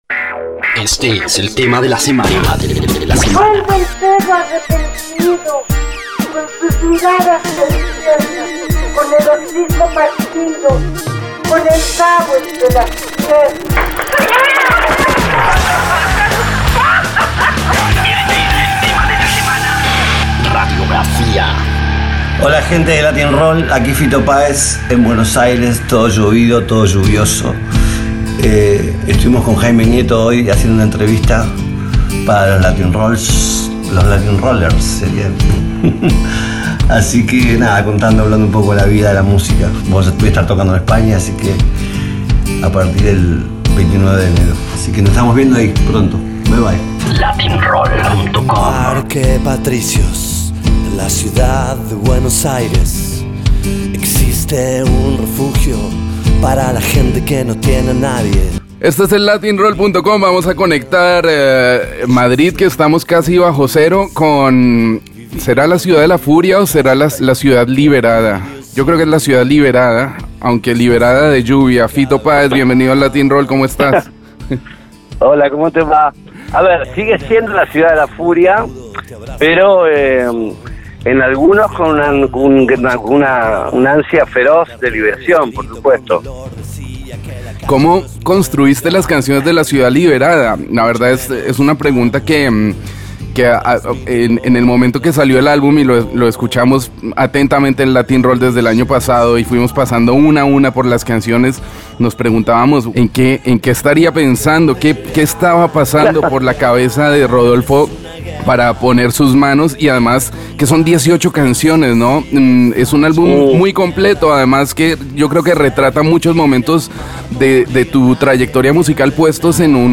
Entrevista exclusiva con Fito Paéz, en la que nos cuenta todos los detalles sobre La Ciudad Liberada, su mas reciente álbum, ademas detalles sobre su tour español.